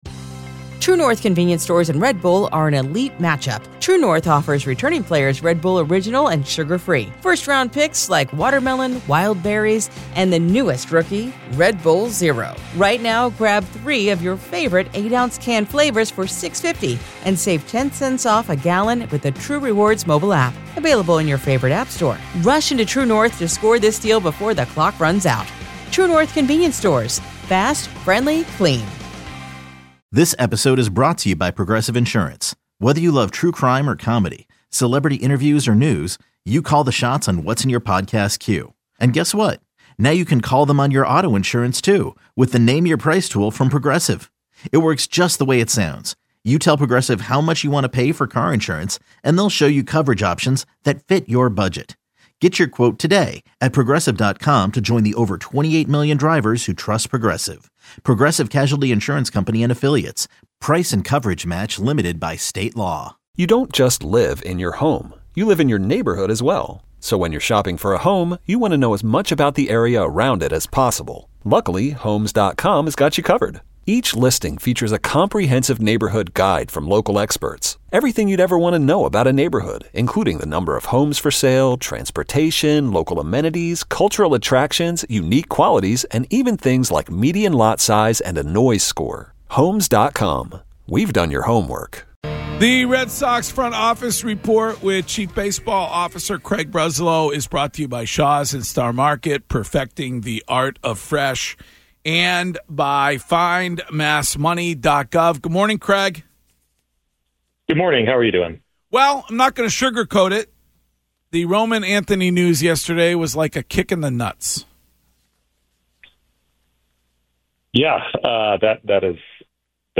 Craig Breslow joins, says rotation needs to step up in Anthony's absence